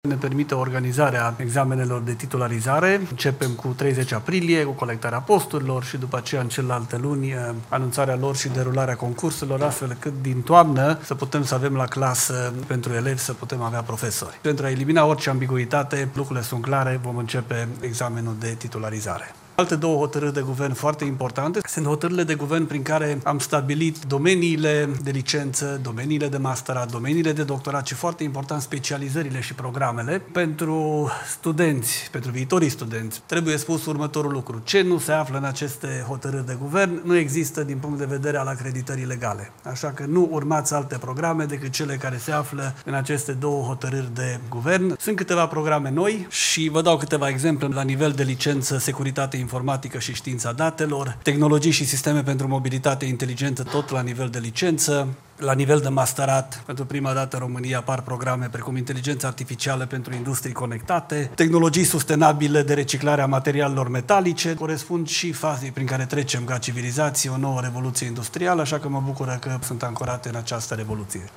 Ministrul Daniel David despre Ordonanţa de Urgenţă adoptată astăzi: